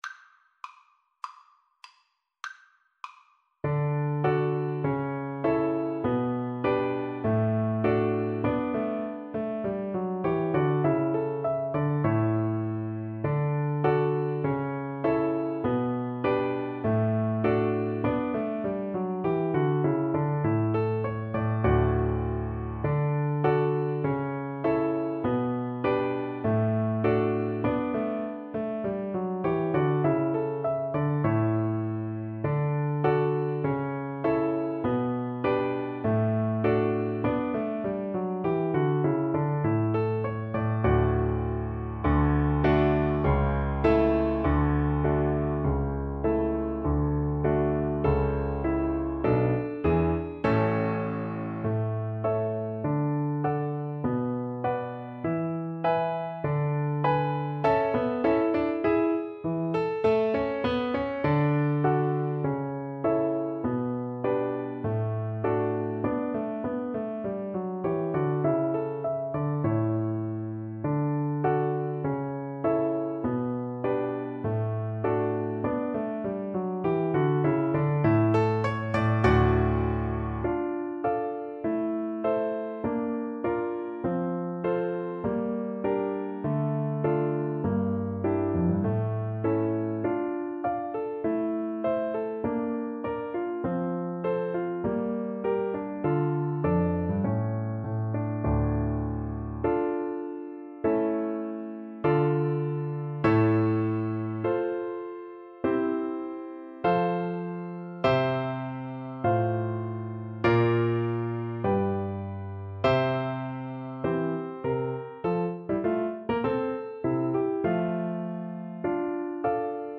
2/2 (View more 2/2 Music)
~ = 100 Allegretto